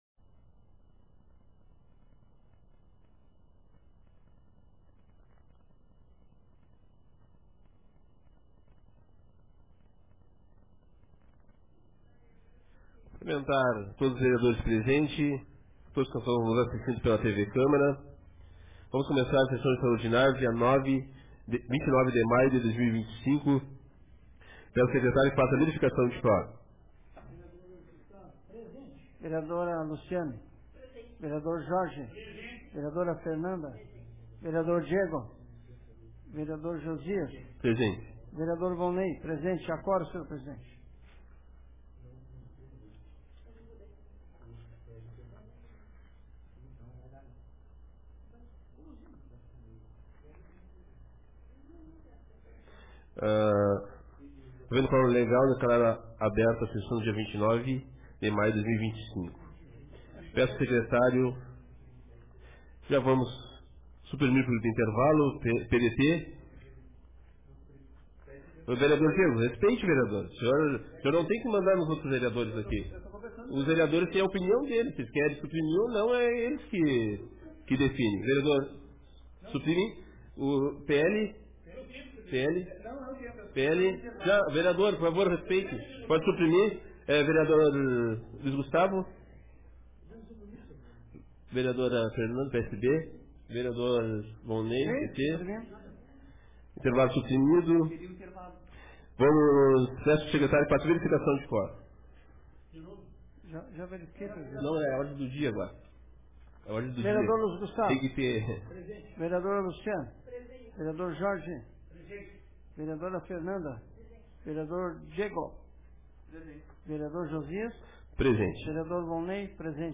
Sessão extraordinária 29/05/2025